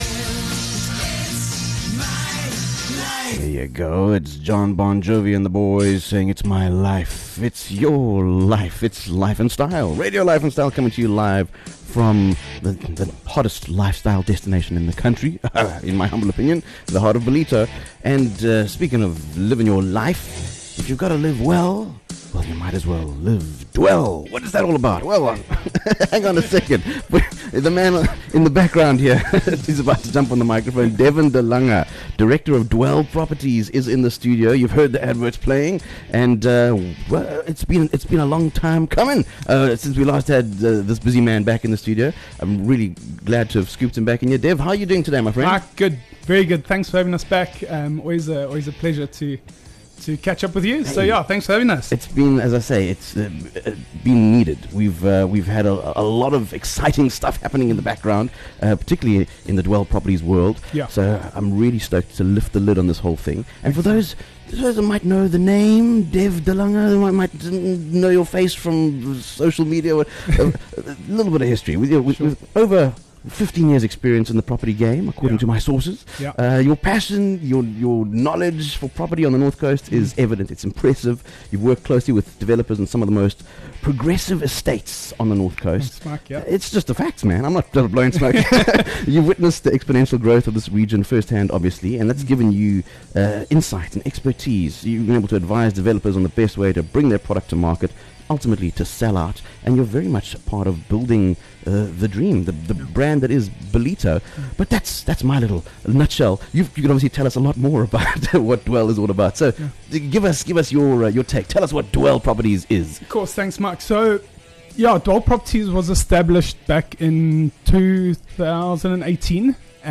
a candid conversation about what sets Dwell apart in the booming North Coast property space